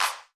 Claps
JJClap (1).wav